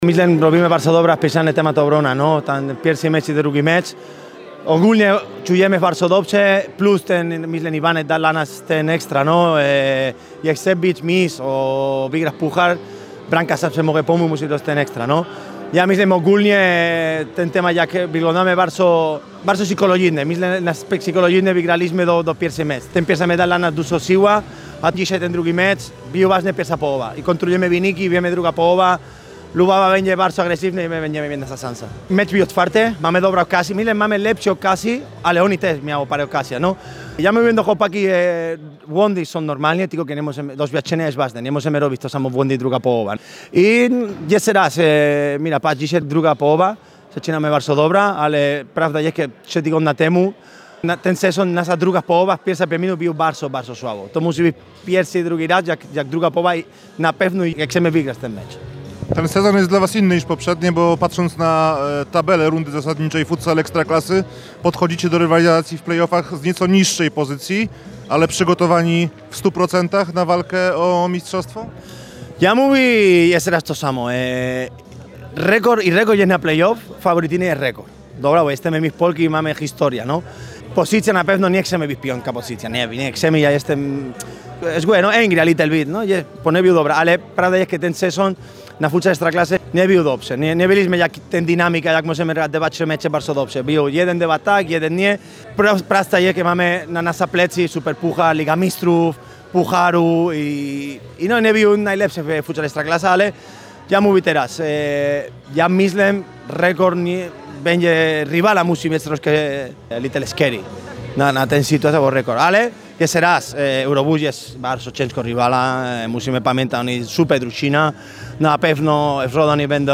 – mówił hiszpański szkoleniowiec.